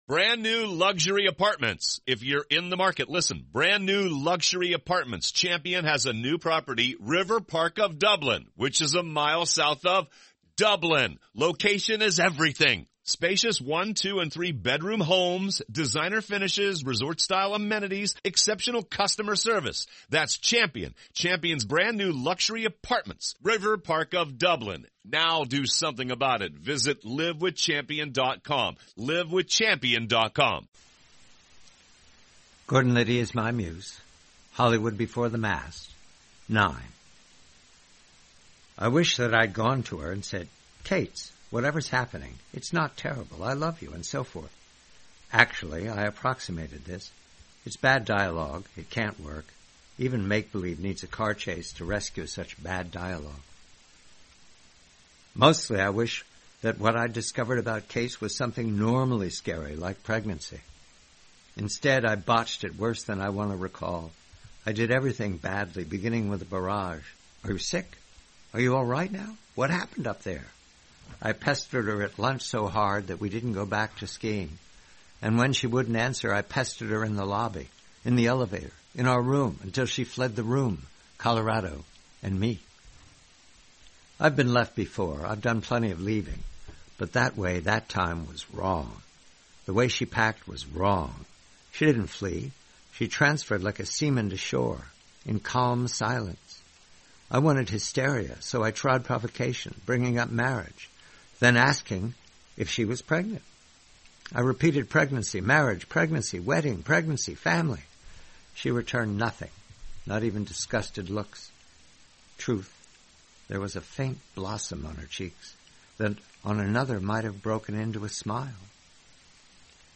Read by the host.